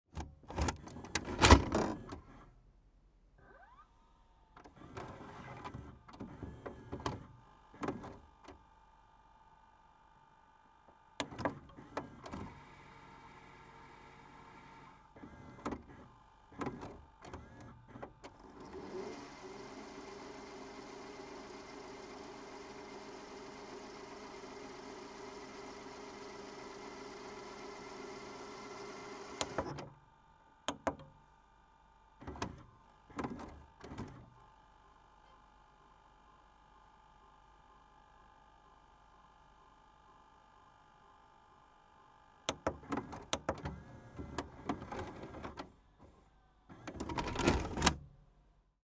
Philips VR 550 video recorder